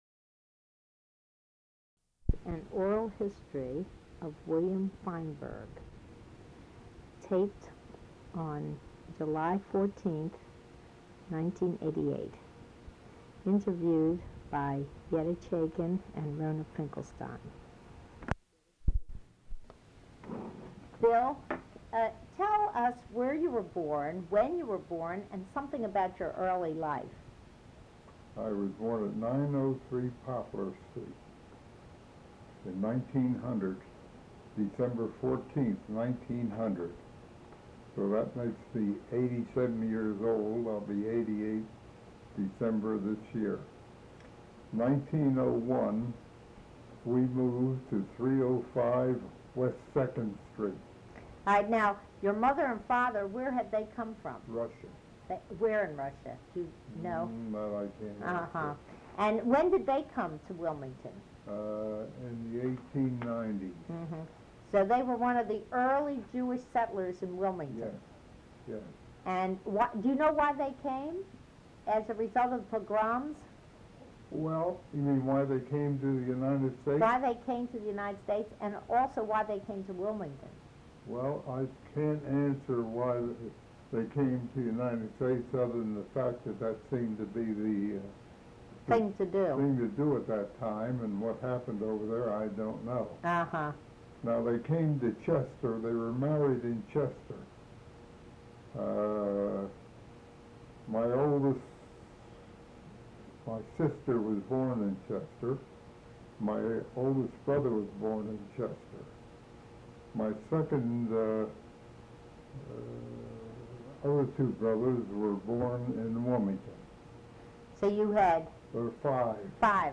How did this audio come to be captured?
Original Format audio cassette tape